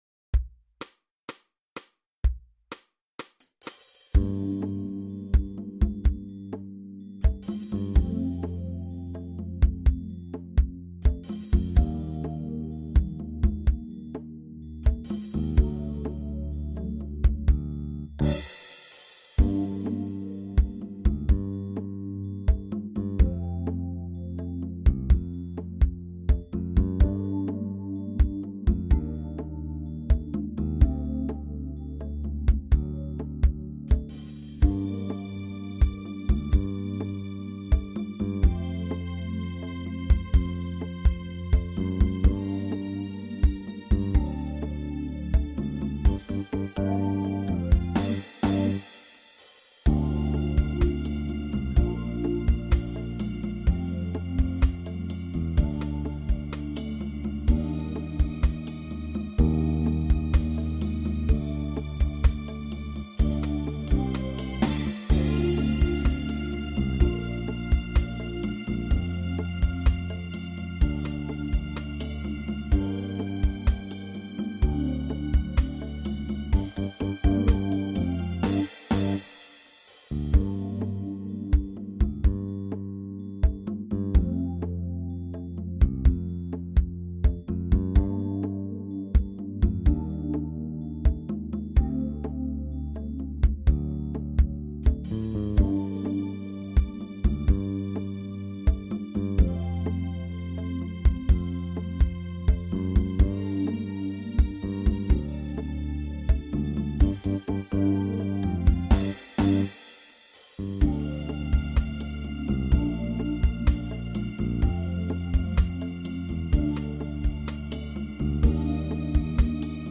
(accompaniment)